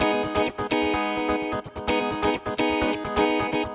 VEH3 Electric Guitar Kit 1 128BPM
VEH3 Electric Guitar Kit 1 - 24 G# min.wav